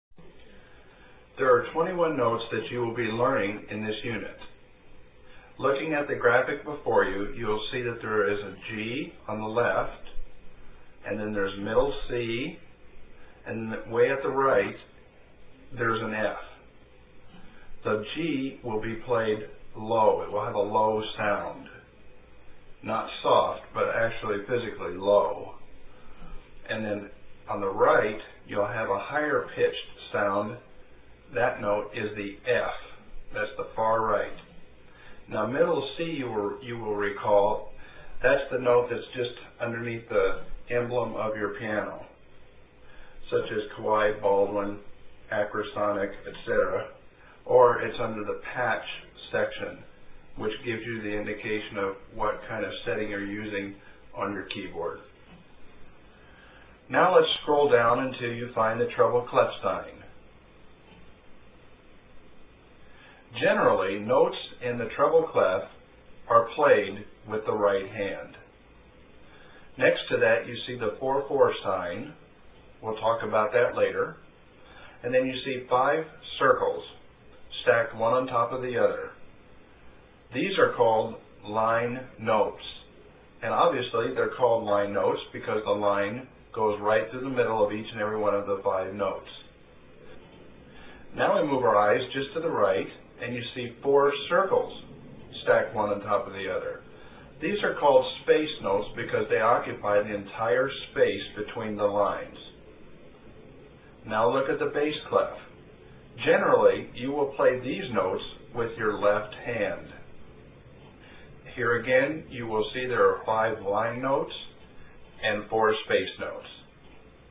there is a piano lesson audio instruction file to be played